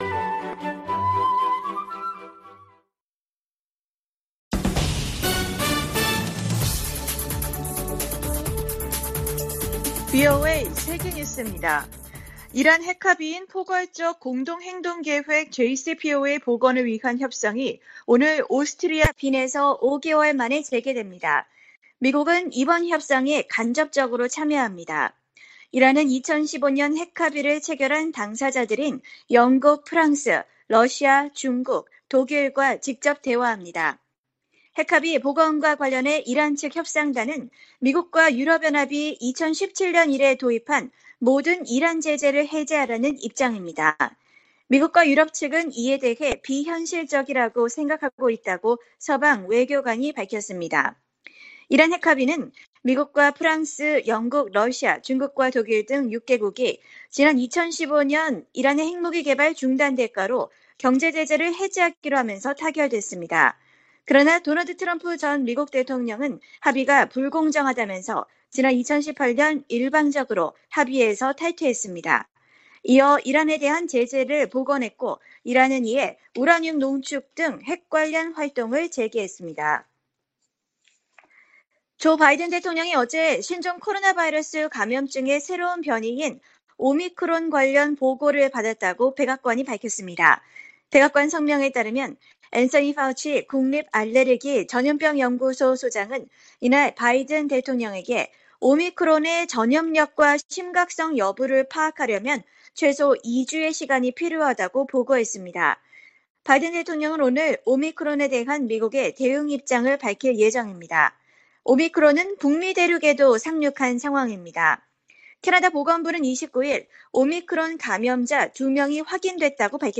VOA 한국어 간판 뉴스 프로그램 '뉴스 투데이', 2021년 11월 29일 2부 방송입니다. 북한이 신종 코로나바이러스 감염증의 새로운 변이종인 ‘오미크론’의 등장에 방역을 더욱 강화하고 있습니다. 북한의 뇌물 부패 수준이 세계 최악이라고, 국제 기업 위험관리사가 평가했습니다. 일본 정부가 추경예산안에 68억 달러 규모의 방위비를 포함시켰습니다.